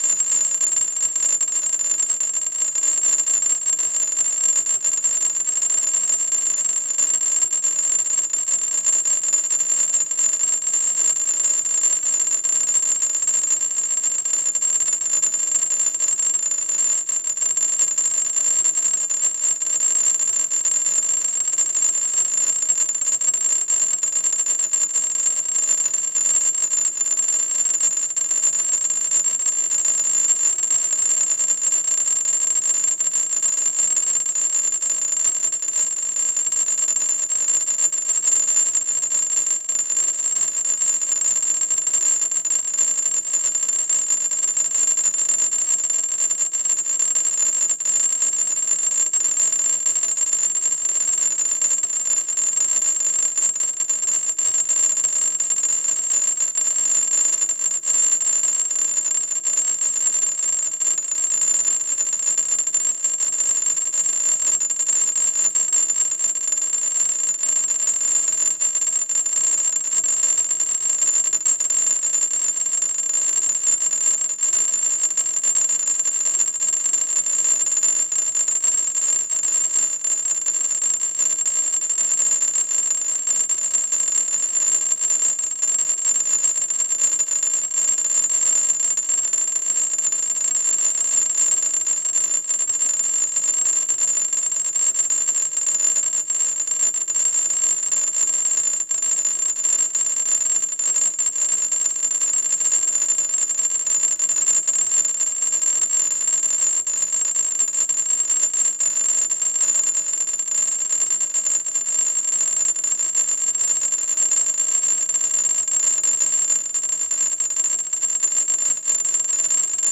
electroacoustic music